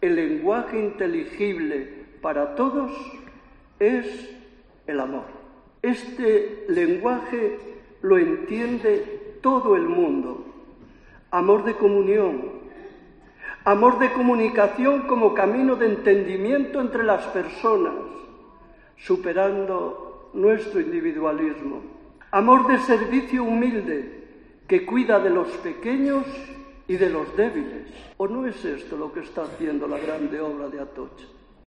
Durante la homilía, Barrio subrayó que es el "amor" el lenguaje que todos entendemos y es el que abandera La Grande Obra de Atocha